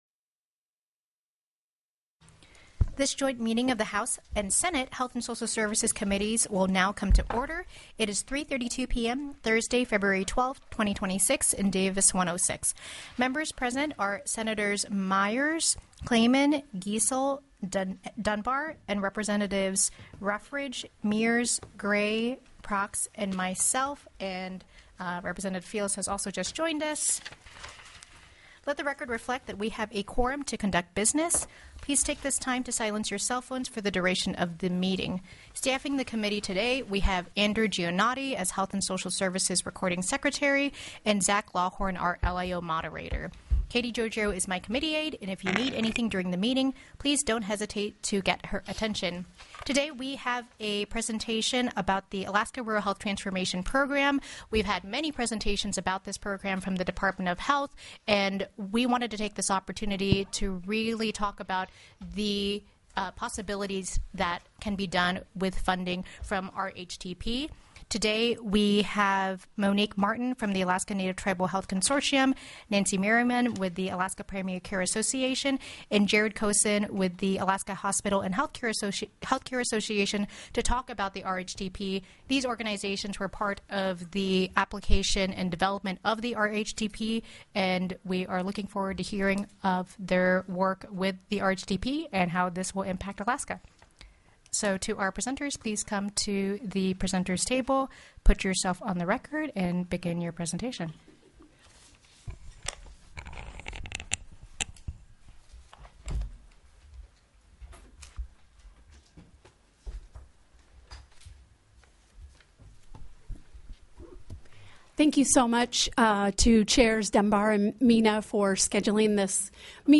The audio recordings are captured by our records offices as the official record of the meeting and will have more accurate timestamps.
Meeting jointly with House Health and Social Services